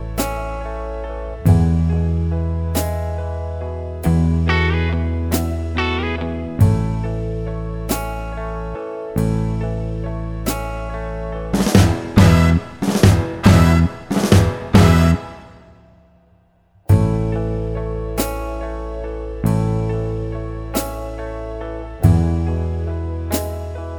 Soul / Motown